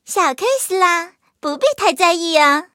M2中坦小破语音2.OGG